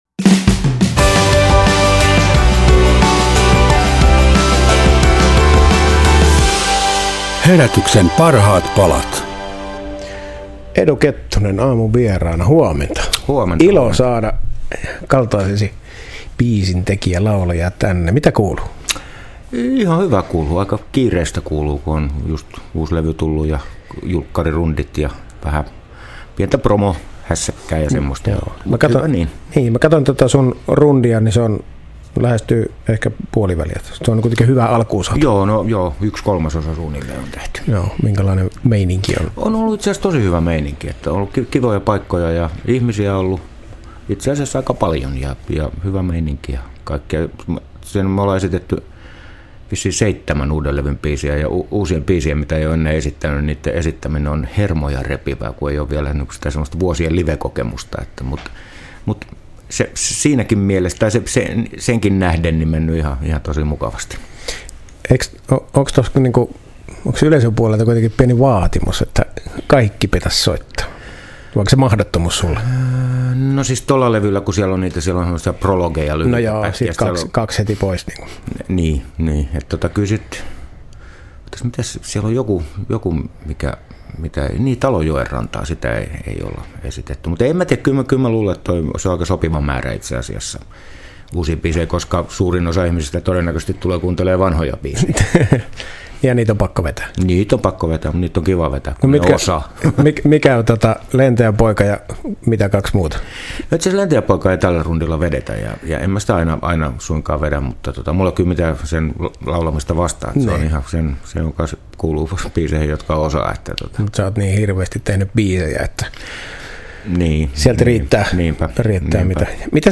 Radio Dein Herätys!-aamulähetyksessä